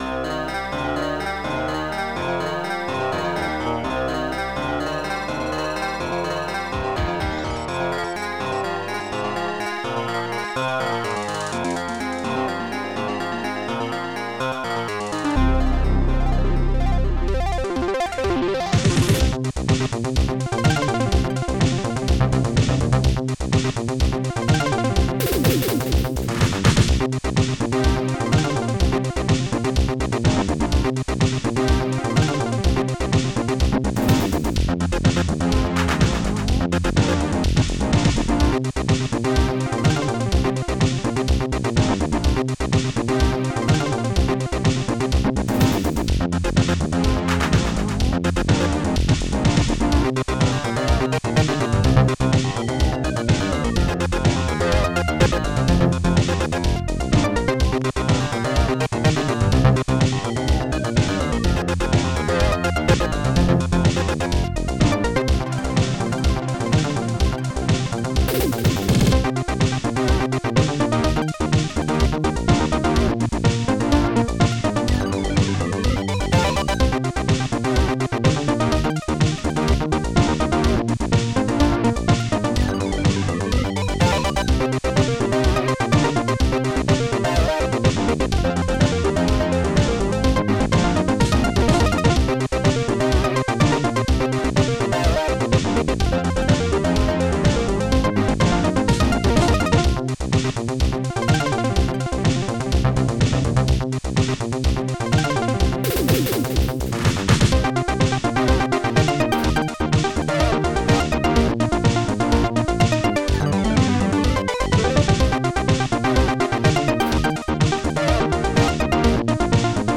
st-04:ANIMATE-KICK
st-01:Snare5
st-04:ANIMATE-CLAP